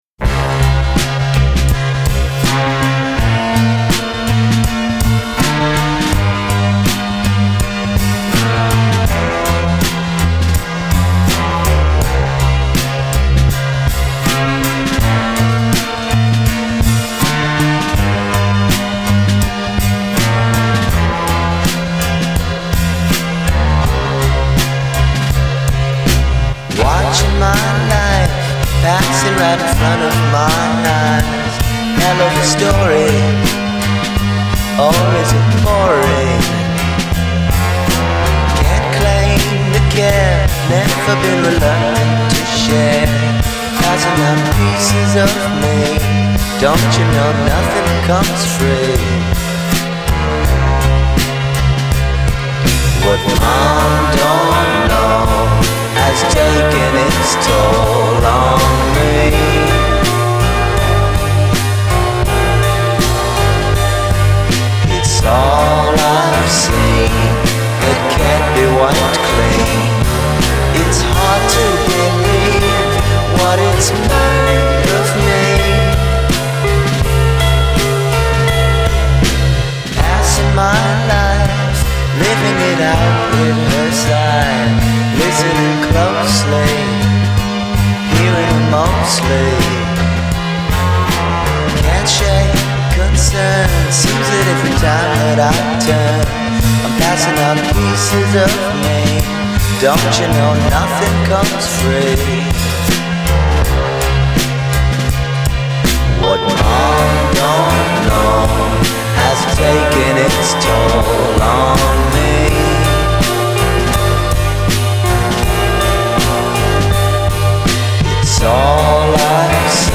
ominous heavy synth um-pa